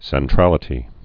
(sĕn-trălĭ-tē)